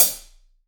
Index of /90_sSampleCDs/AKAI S6000 CD-ROM - Volume 3/Drum_Kit/AMBIENCE_KIT3
AMB CLHH2 -S.WAV